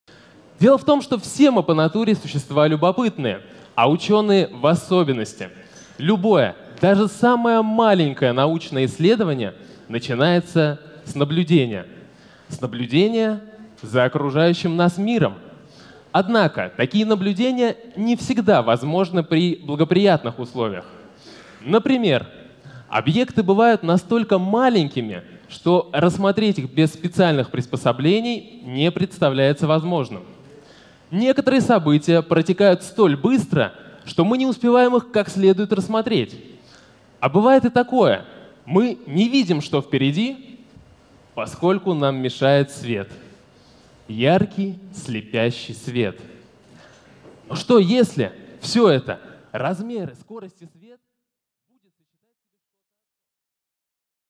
Аудиокнига Любопытство — не порок | Библиотека аудиокниг
Прослушать и бесплатно скачать фрагмент аудиокниги